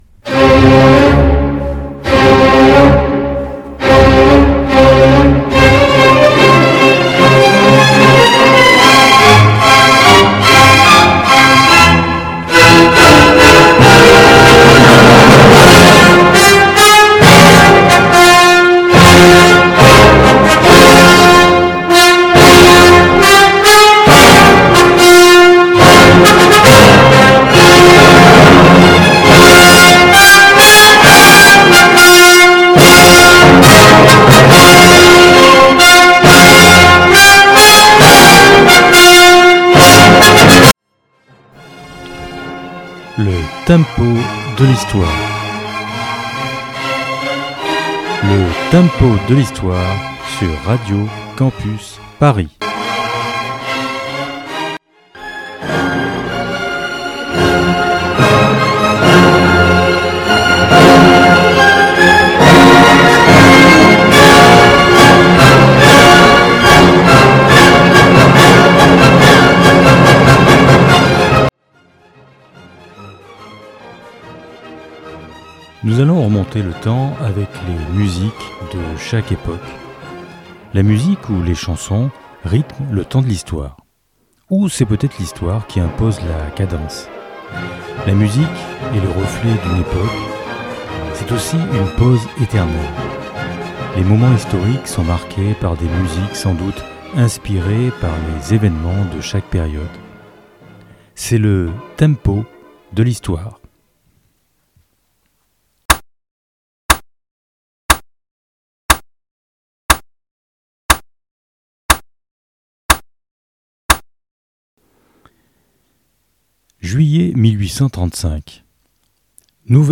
Type Musicale Pop & Rock